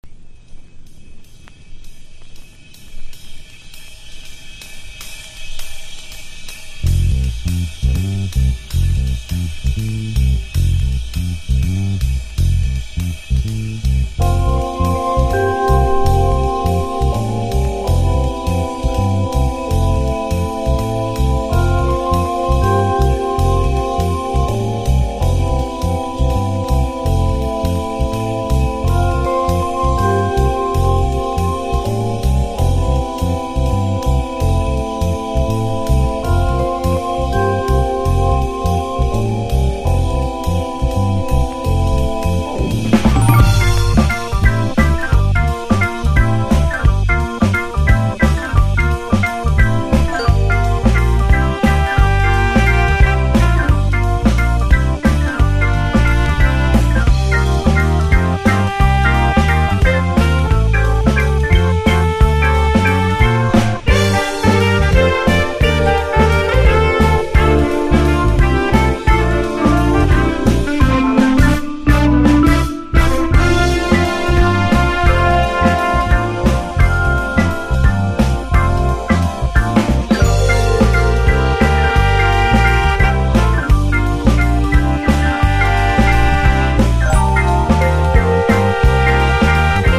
心地よいお洒落なラウンジジャズ満載です。